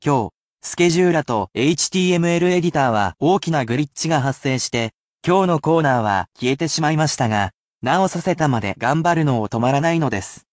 I will also read aloud the sentences for you, however those will be presented at natural speed.